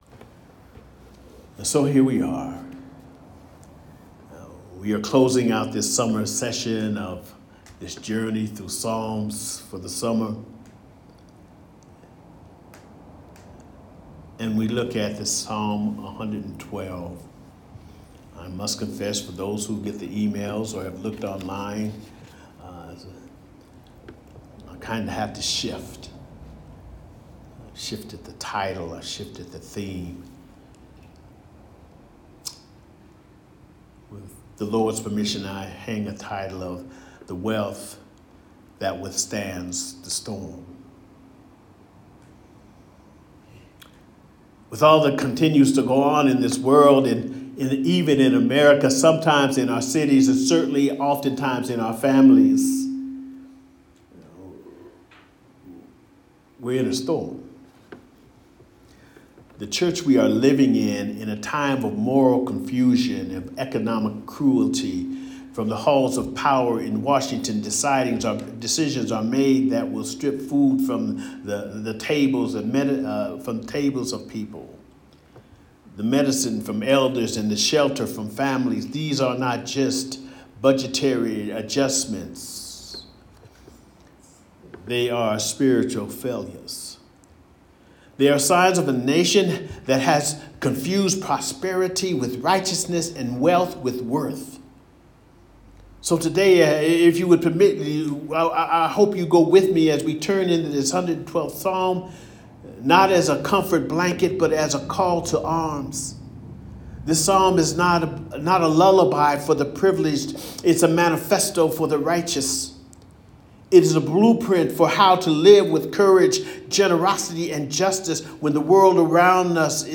Audio Sermon Archive